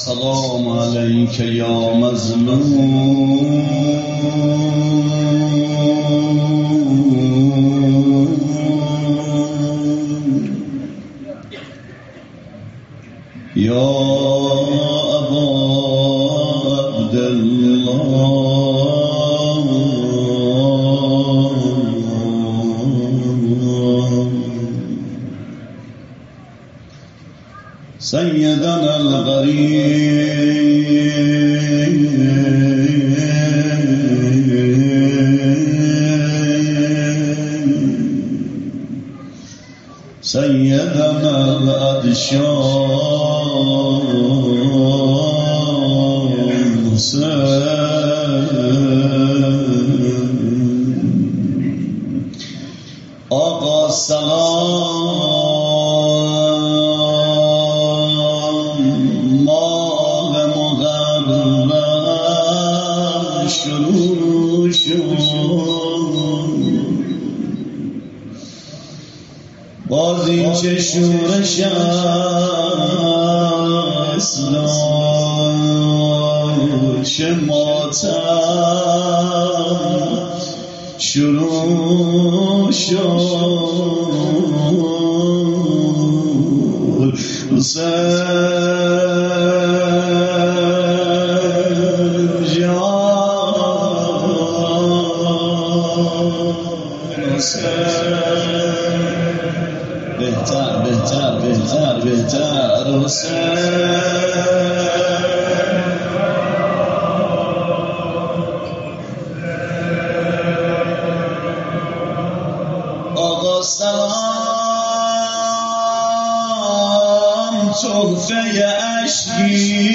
روضه حضرت مسلم